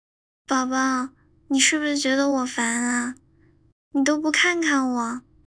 声音克隆效果：
克隆效果：